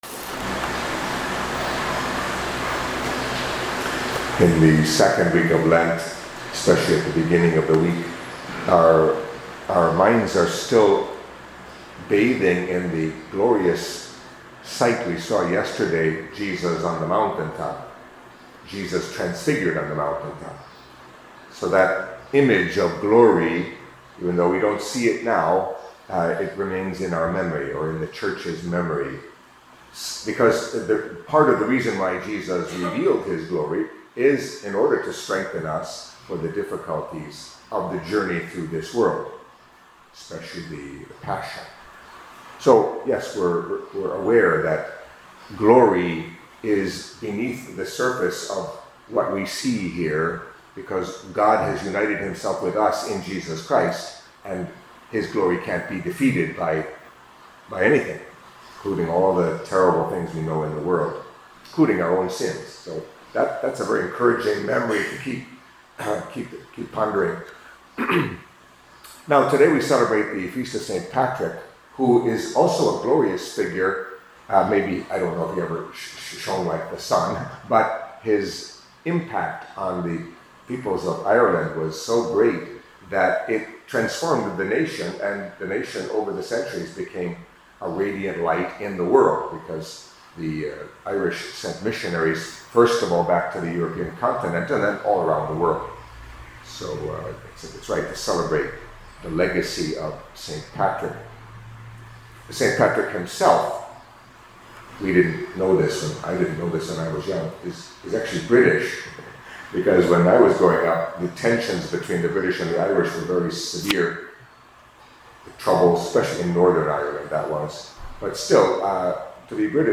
Catholic Mass homily for Monday of the Second Week of Lent